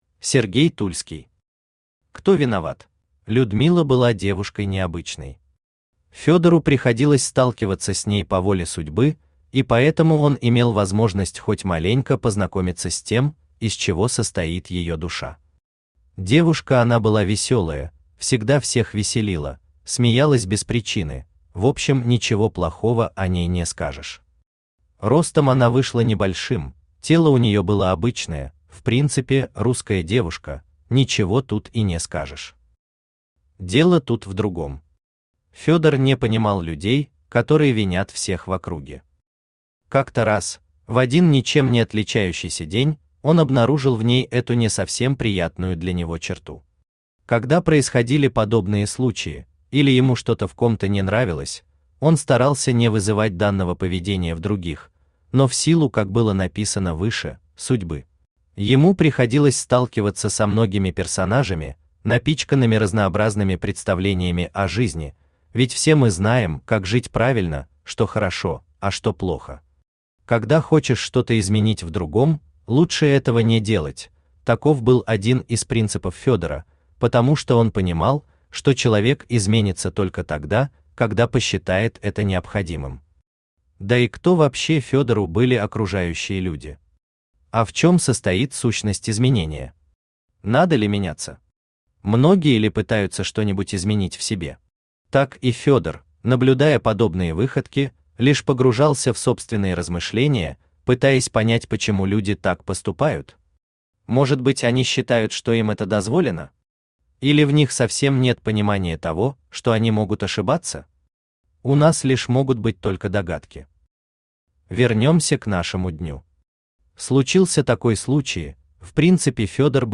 Аудиокнига Кто виноват?
Автор Сергей Тульский Читает аудиокнигу Авточтец ЛитРес.